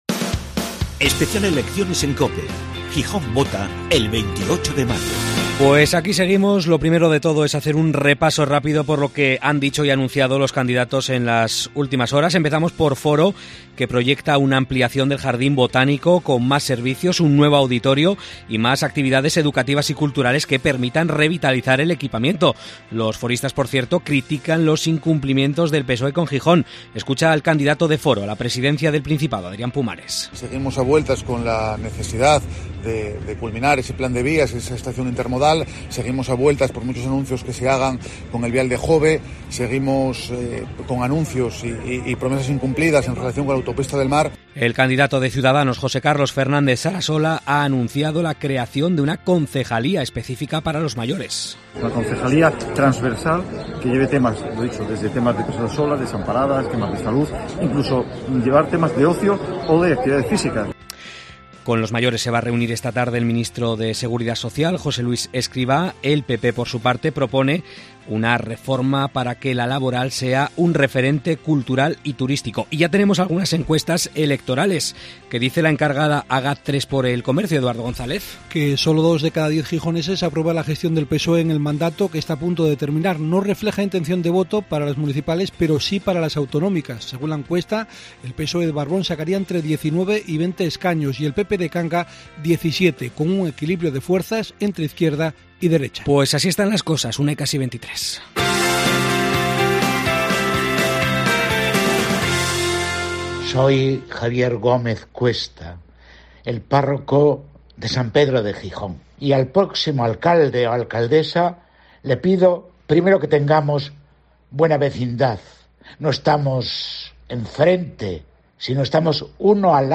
Gijón está en campaña electoral. En COPE hablamos con los candidatos, pero también escuchamos a los ciudadanos, sus inquietudes y peticiones.